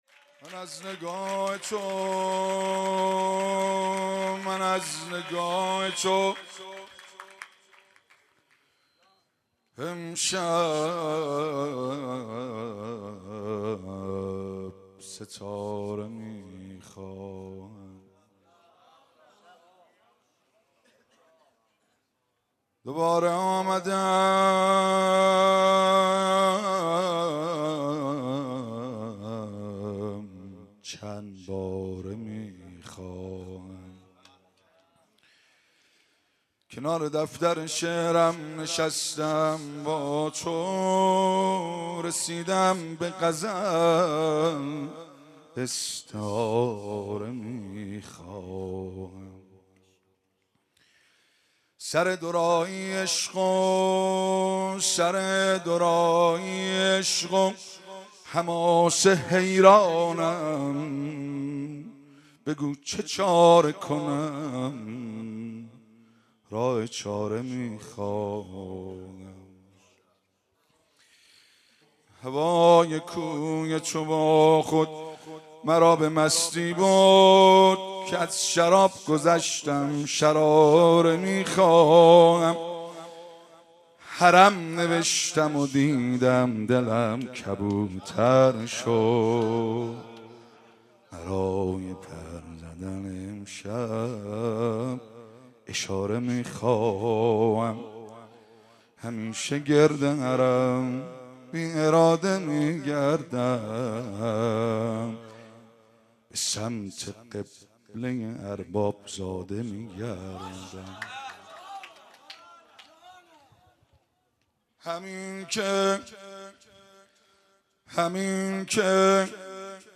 شب میلاد حضرت علی اکبر(ع) / هیئت فاطمیون قم
سرود
مدح